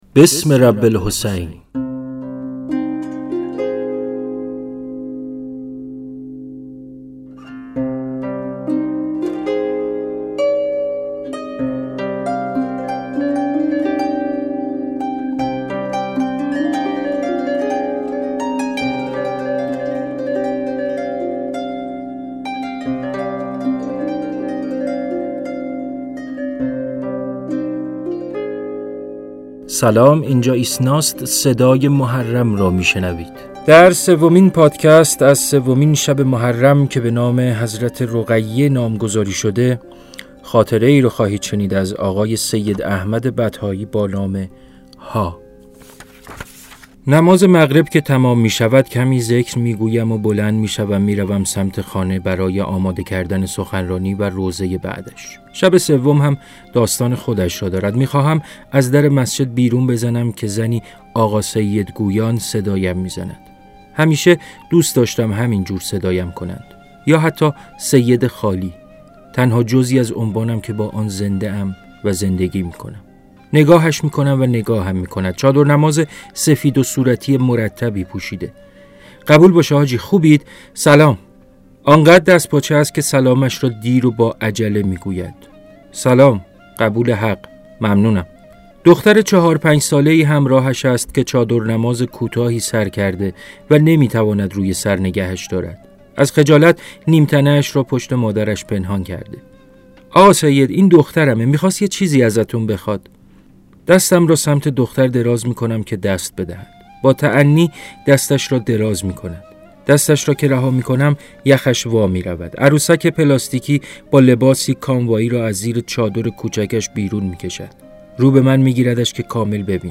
در هر اپیزود، ابتدا خاطره‌ای با مضامین محرم را خواهید شنید که از دو کتاب «کاشوب» و «زان‌تشنگان» انتخاب شده است و در ادامه، شعری متناسب با نامگذاری هر شب از این دهه را می‌شنوید. برای پایان هر قسمت نیز قطعه‌ای موسیقی انتخاب شده است.